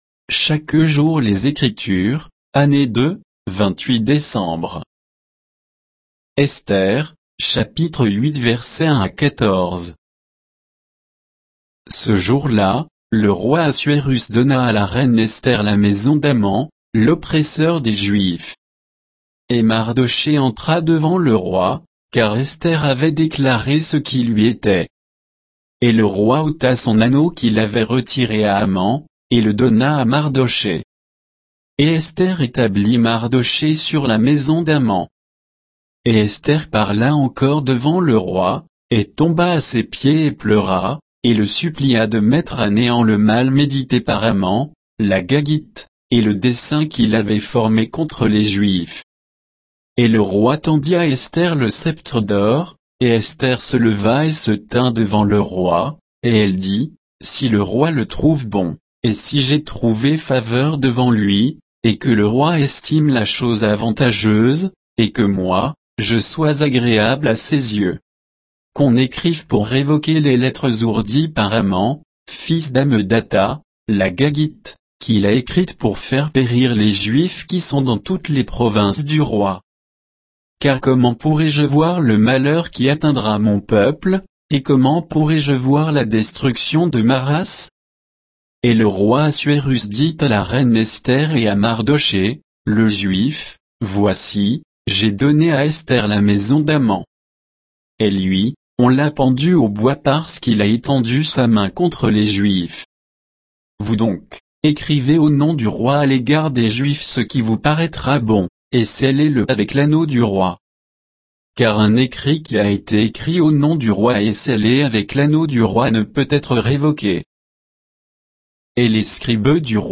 Méditation quoditienne de Chaque jour les Écritures sur Esther 8, 1 à 14